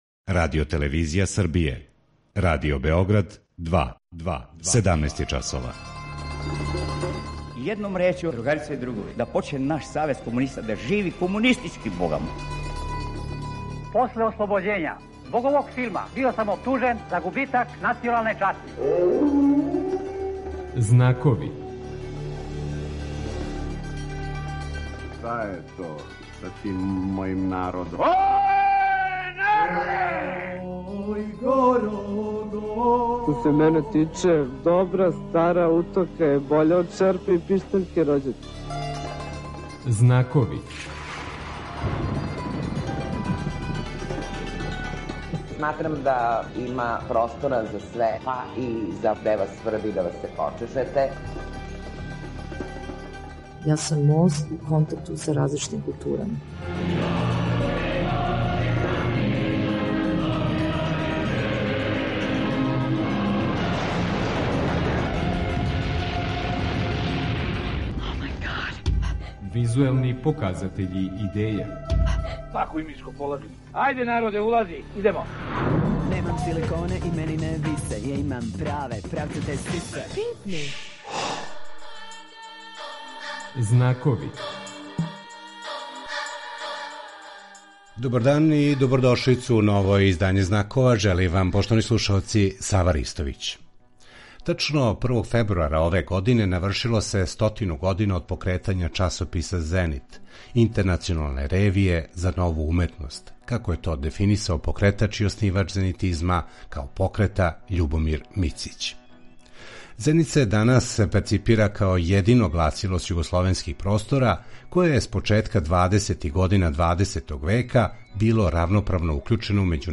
У данашњој емисији преносимо најинтересантније делове ове промоције.